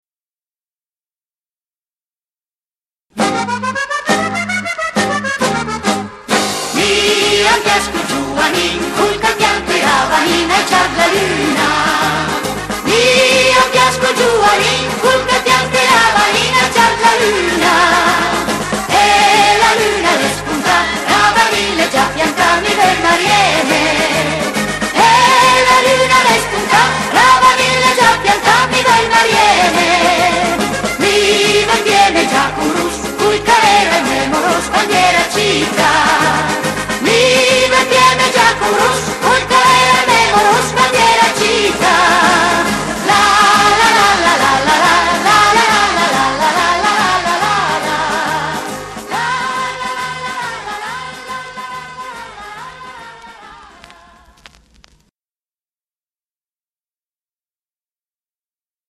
canto popolare piemontese